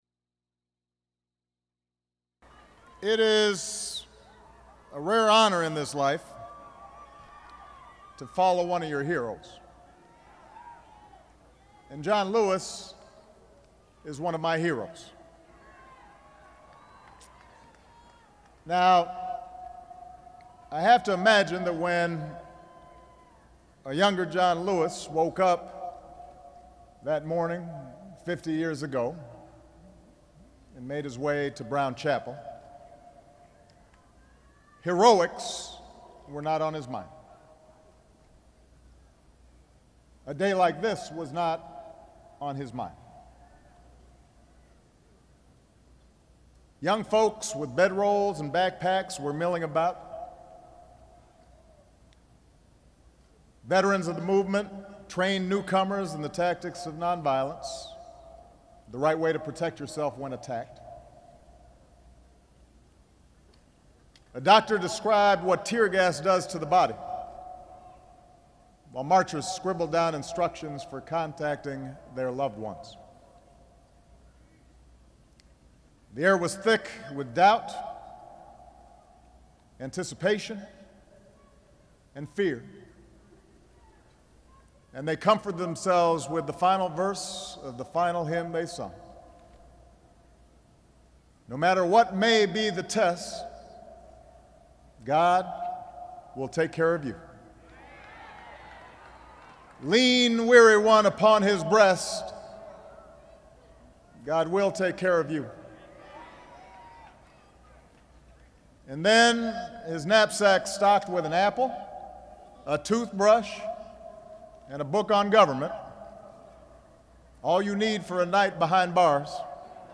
President Barack Obama, standing at the foot of the Edmund Pettus Bridge in Selma, Alabama, commemorates the 50th anniversary of the Selma to Montgomery civil rights marches